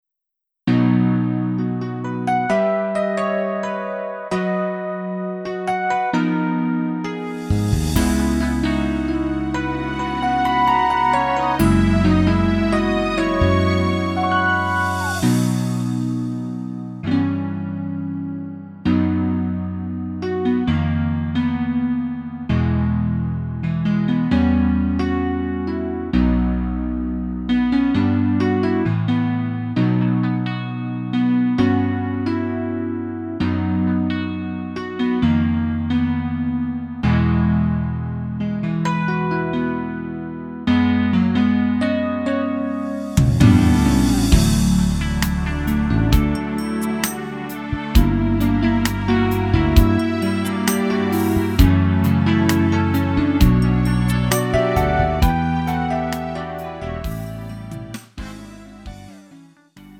음정 원키 4:15
장르 가요 구분 Lite MR
Lite MR은 저렴한 가격에 간단한 연습이나 취미용으로 활용할 수 있는 가벼운 반주입니다.